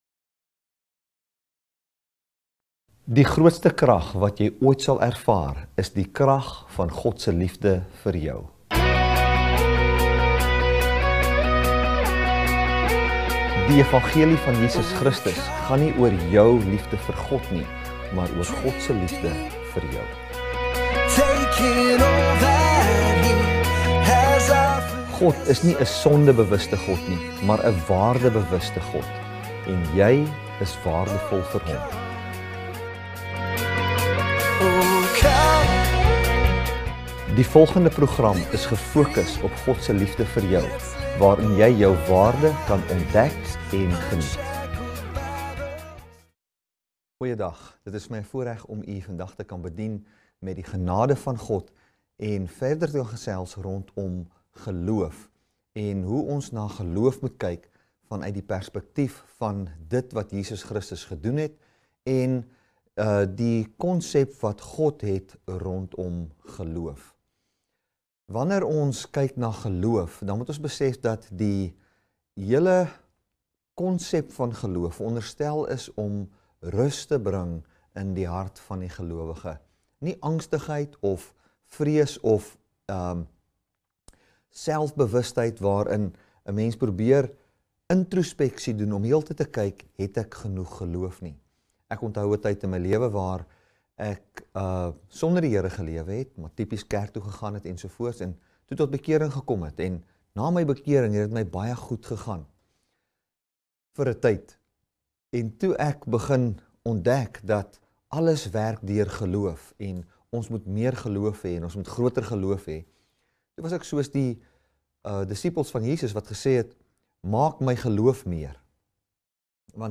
Audio Messages | Dynamic Love Ministries